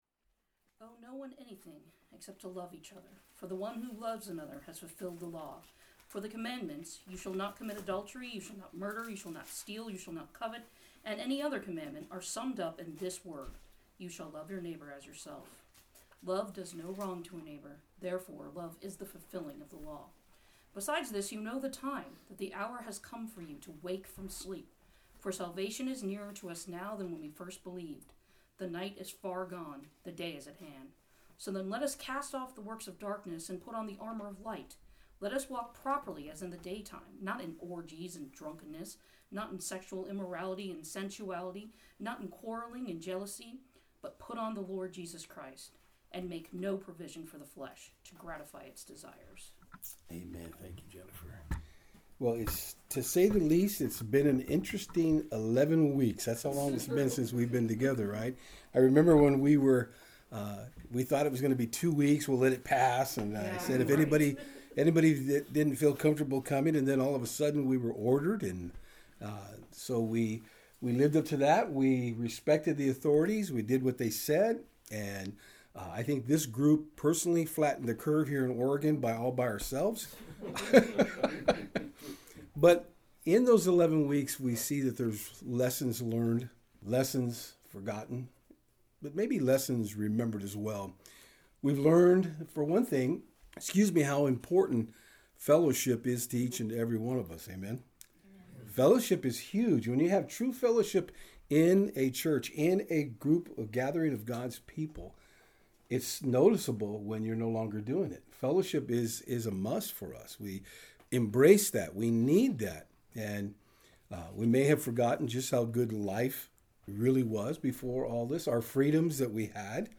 Service Type: Saturdays on Fort Hill